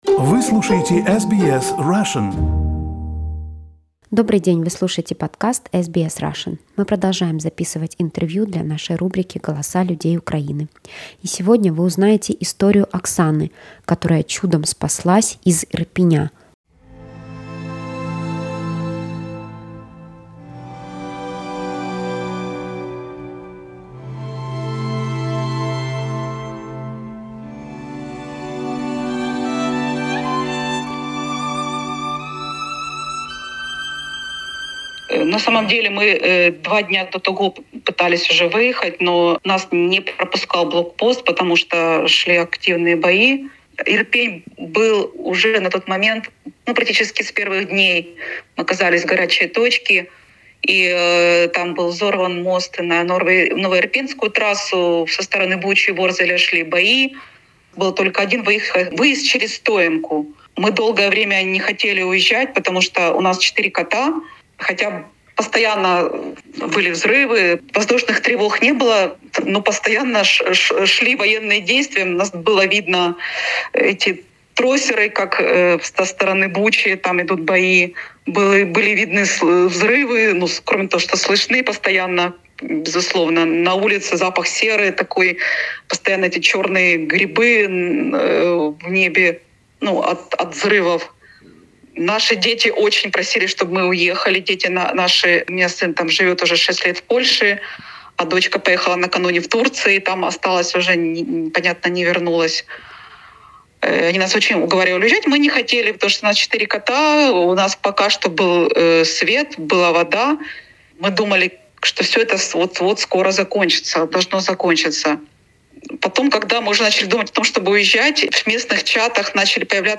В аудио и текстовых сообщениях жители Украины рассказывают о войне.